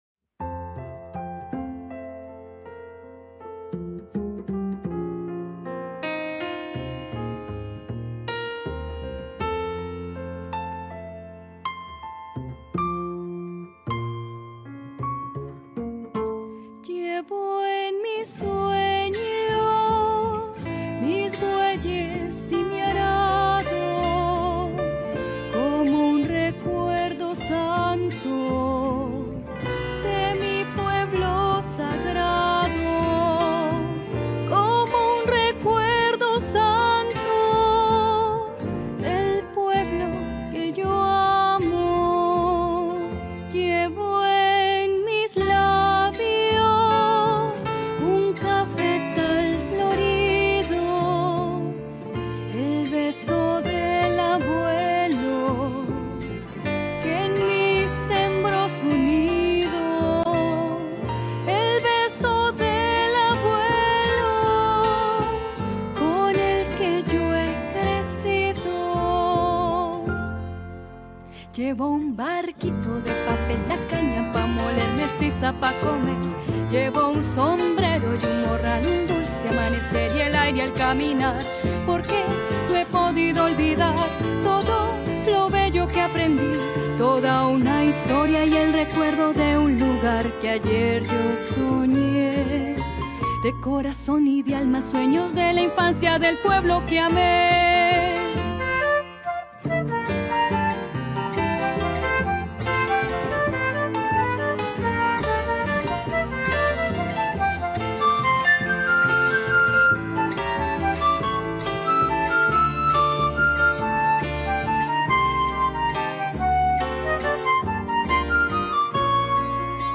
Pasillo